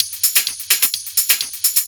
VTDS2 Song Kits 128 BPM Pitched Your Life